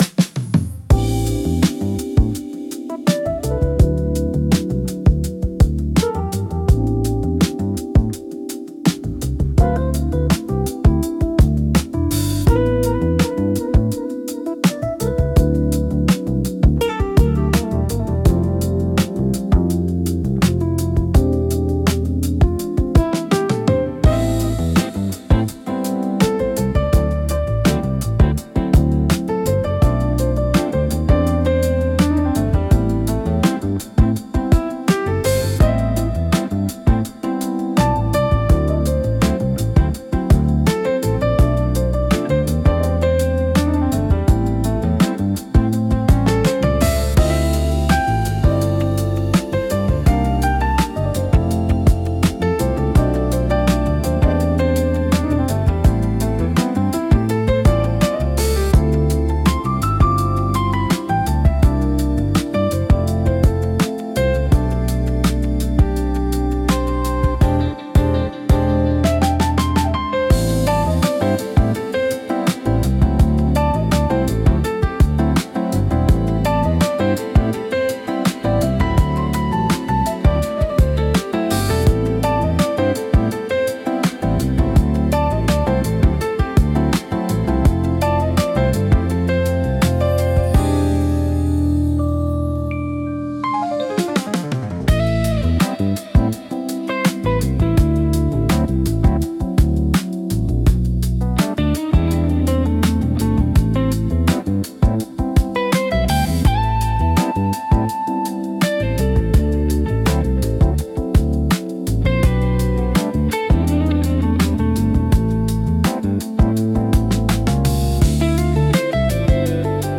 リラックス効果が高く、会話の邪魔をせず心地よい背景音として居心地の良さを高めます。